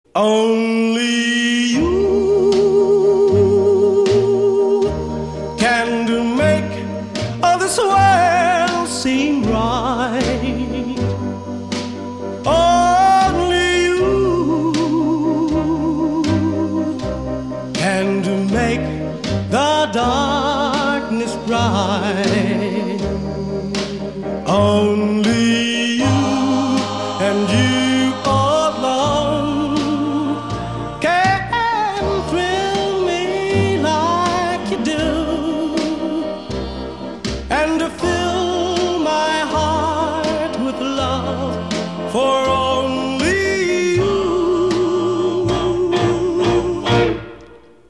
• Качество: 112, Stereo
спокойные
романтичные
ретро
блюз